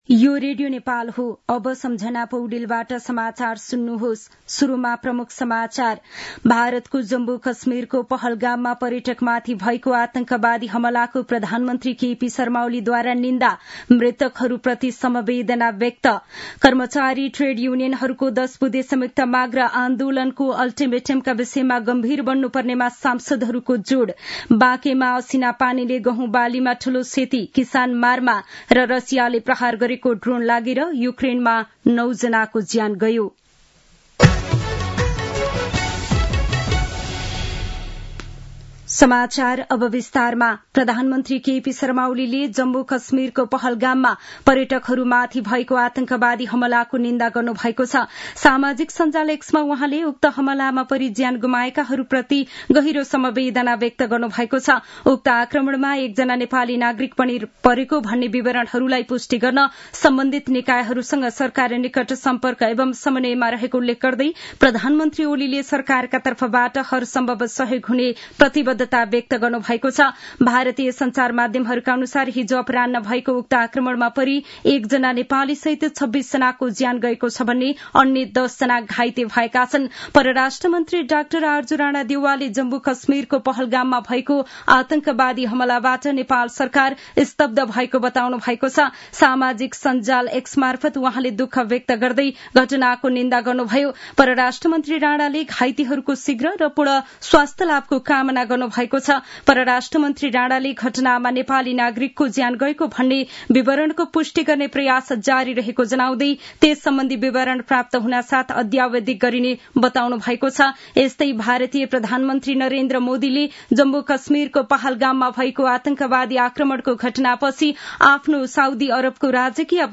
दिउँसो ३ बजेको नेपाली समाचार : १० वैशाख , २०८२
3-pm-news-1-8.mp3